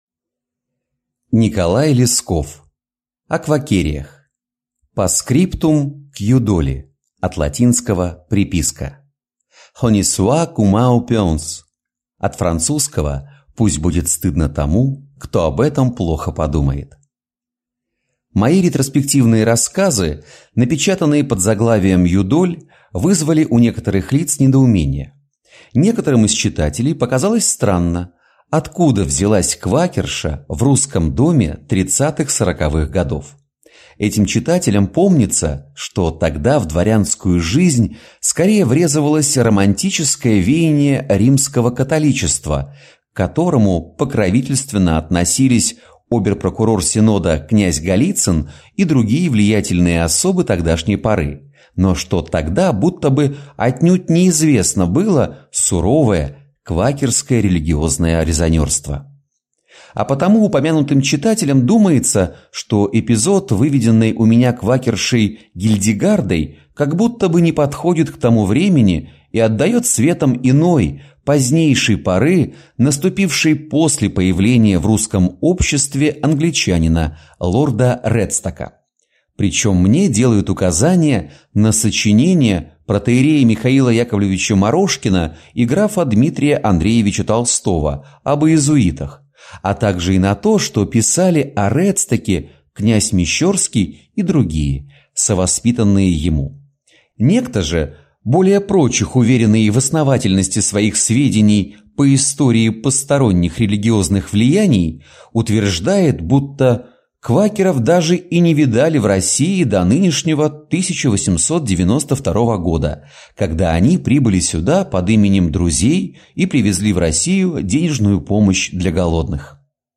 Аудиокнига О «Квакереях» | Библиотека аудиокниг